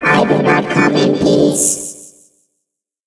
evil_rick_start_vo_01.ogg